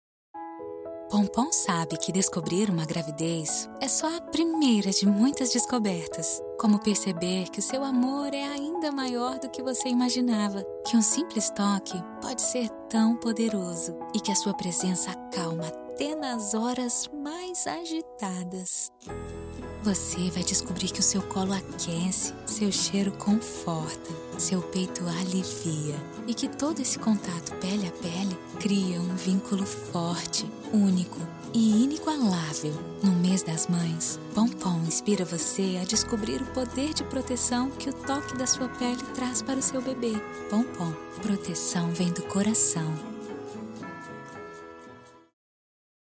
Natural, Reliable, Friendly, Soft, Corporate
Commercial